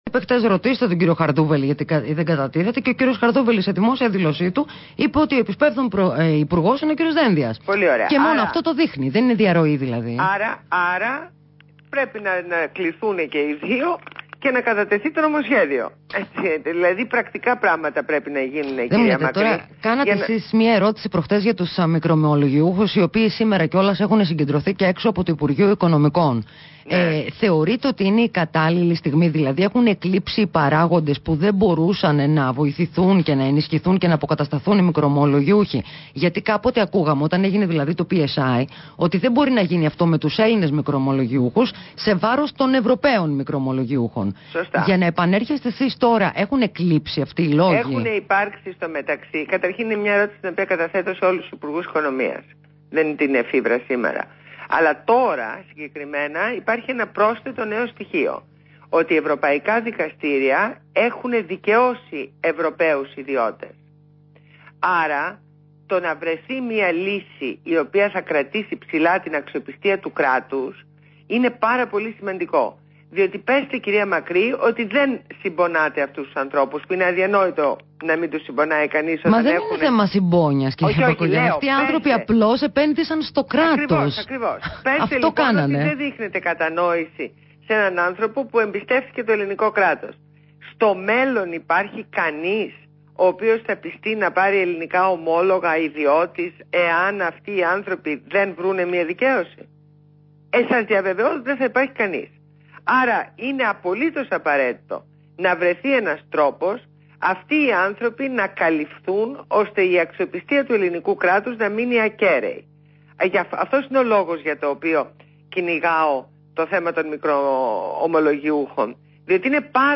Συνέντευξη στο ραδιόφωνο REALfm